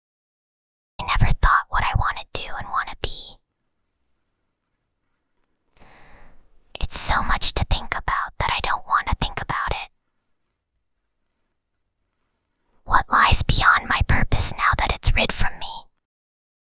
File:Whispering Girl 15.mp3
Whispering_Girl_15.mp3